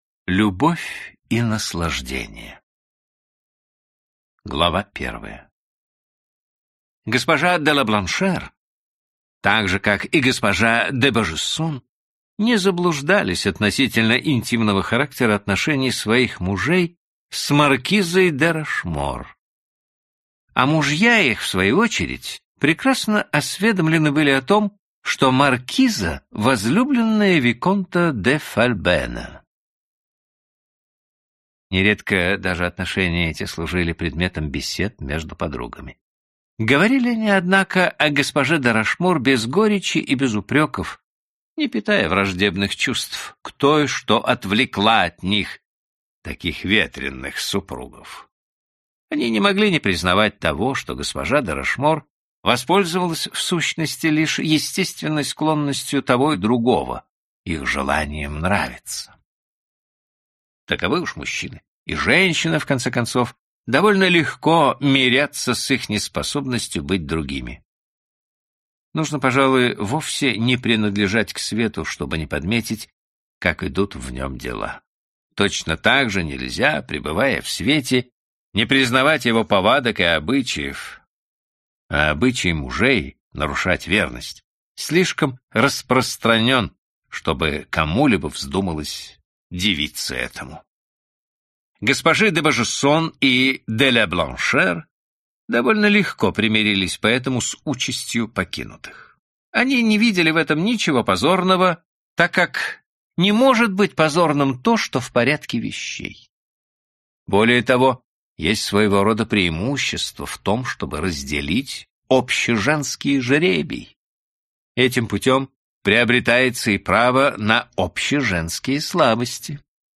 Аудиокнига Любовь и наслаждение. В кафе Квадри | Библиотека аудиокниг